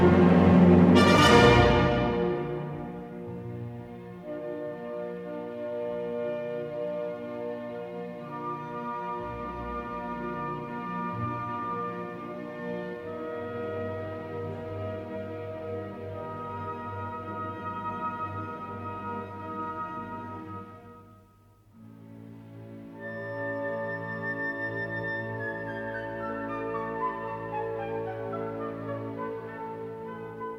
si bémol majeur
"templateExpression" => "Musique orchestrale"